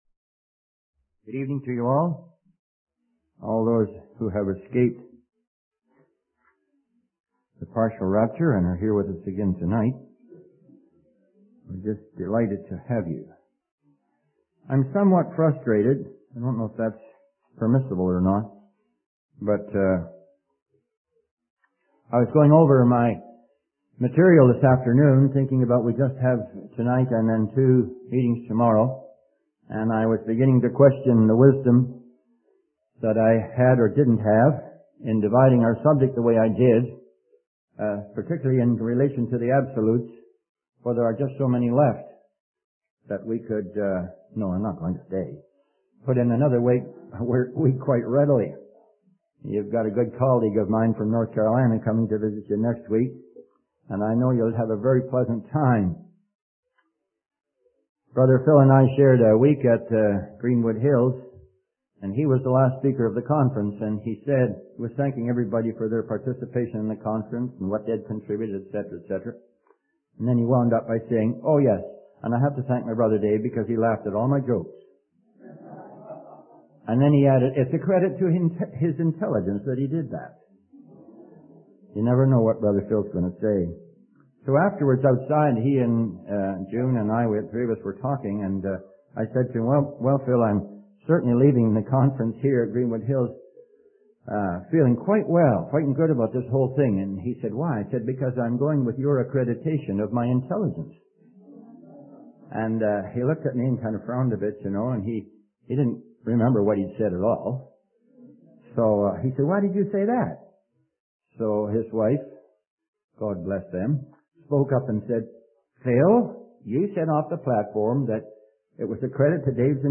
In this sermon, the preacher emphasizes the transformative power of love. He uses the example of Mary, who was so moved by her love for Jesus that she took action.